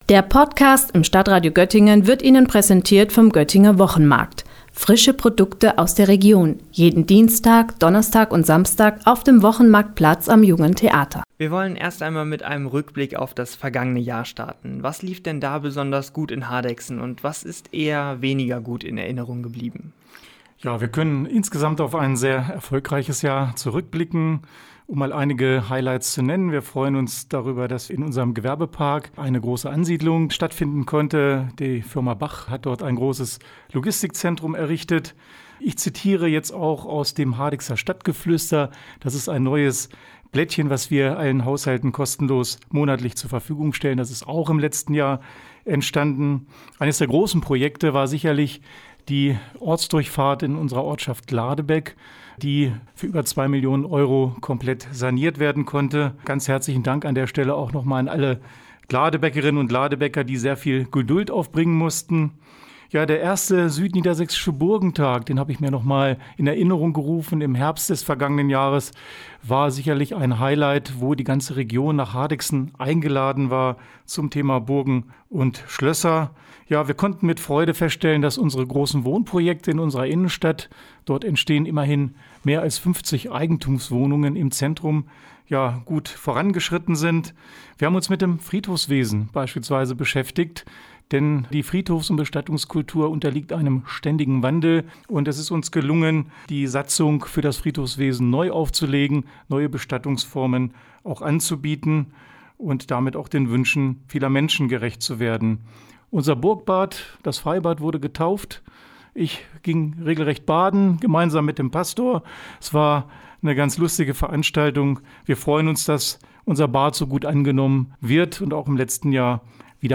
Beiträge > Zwischenbilanz zum Leitbild „Hardegsen 2030 - Stadt mit Zukunft“: Bürgermeister Michael Kaiser im Interview - StadtRadio Göttingen